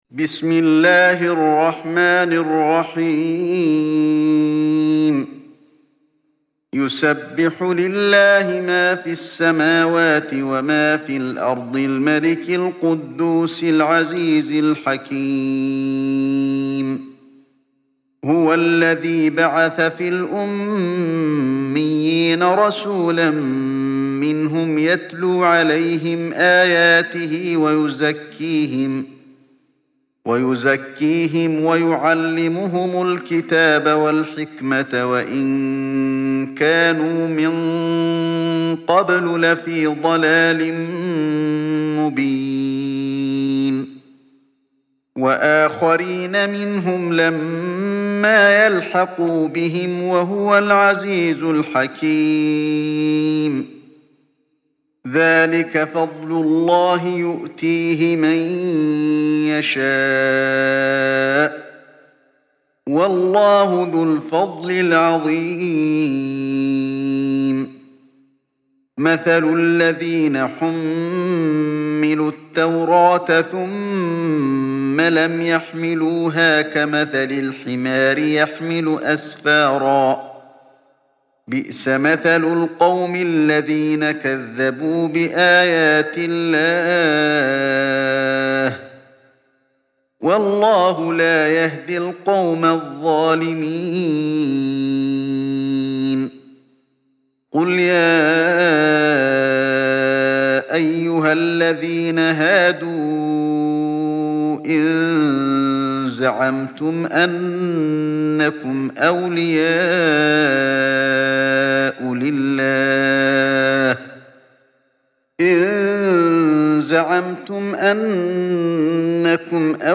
تحميل سورة الجمعة mp3 بصوت علي الحذيفي برواية حفص عن عاصم, تحميل استماع القرآن الكريم على الجوال mp3 كاملا بروابط مباشرة وسريعة